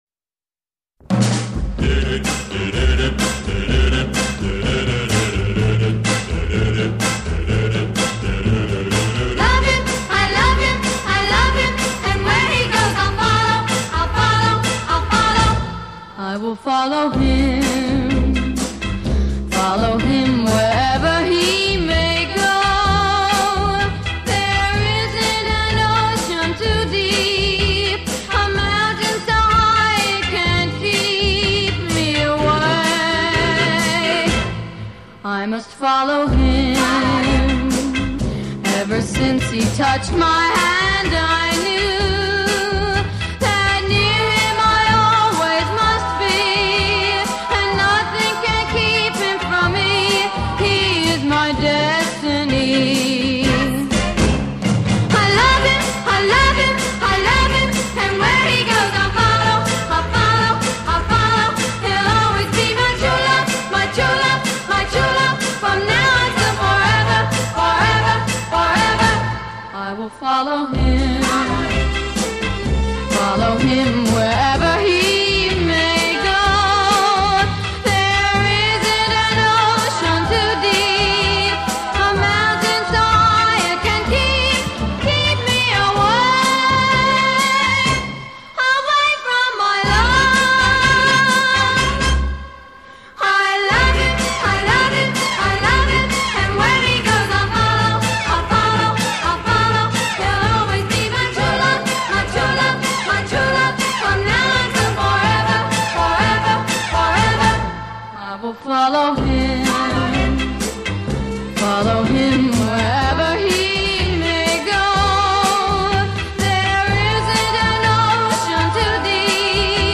录音年代久远，音质比较差，希望有乐友能提供更佳录音，以享网友。